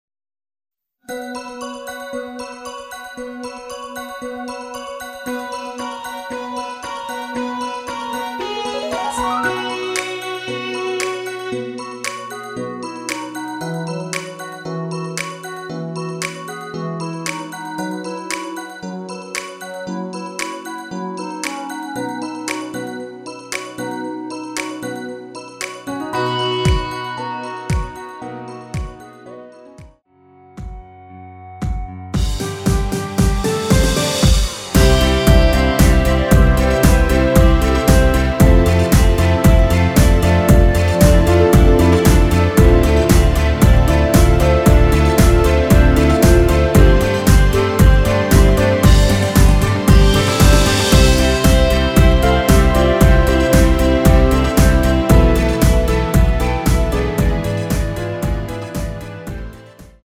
엔딩이 페이드 아웃이라 노래 부르기 좋게 엔딩 만들었습니다.
원키에서(-4)내린 멜로디 포함된 MR입니다.
노래방에서 노래를 부르실때 노래 부분에 가이드 멜로디가 따라 나와서
앞부분30초, 뒷부분30초씩 편집해서 올려 드리고 있습니다.
중간에 음이 끈어지고 다시 나오는 이유는